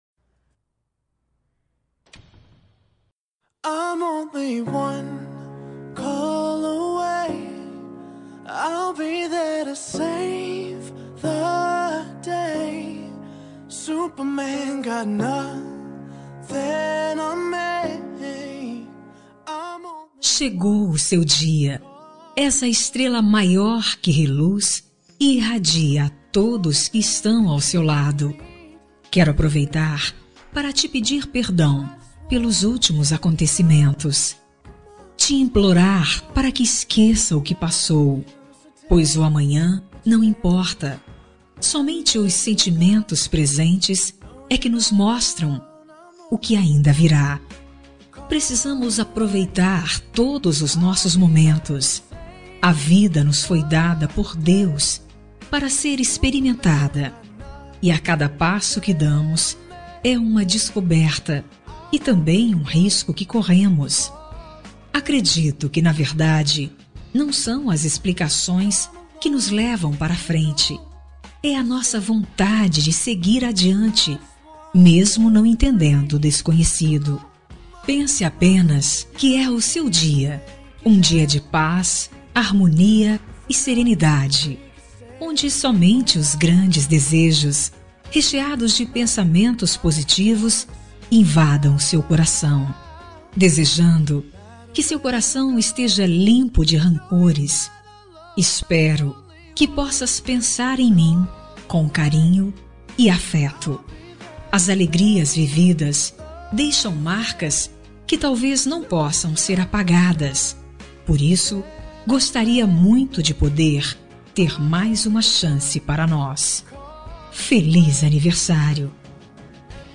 Aniversário Romântico com Reconciliação – Voz Feminina – Cód: 5460